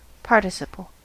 Ääntäminen
Ääntäminen US : IPA : /pɑːr.tɪˌsɪ.pəl/ RP : IPA : /pɑːˈtɪsɪpəl/ Lyhenteet ja supistumat (kielioppi) part.